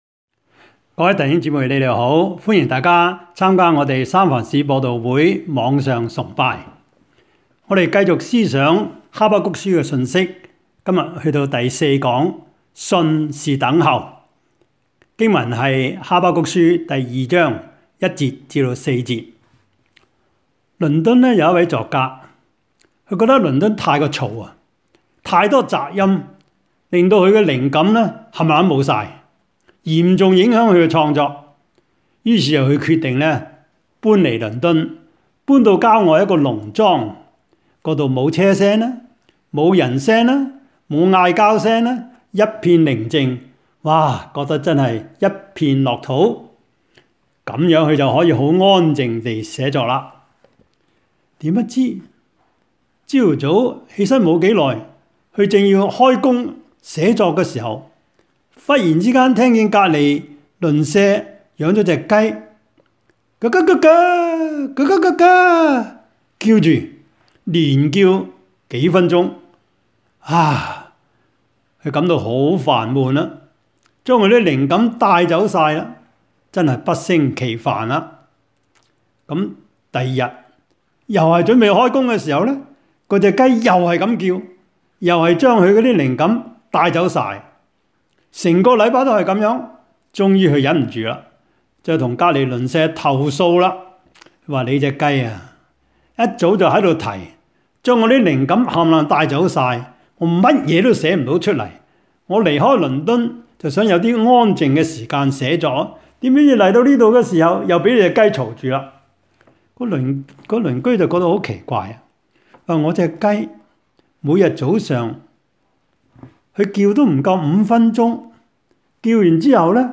三藩市播道會主日網上崇拜
Habakkuk-Sermon-4.mp3